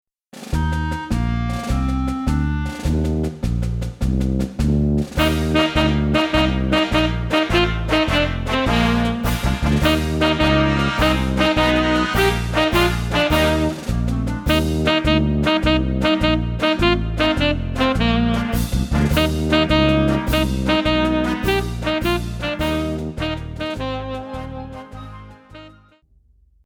Besetzung: Instrumentalnoten für Saxophon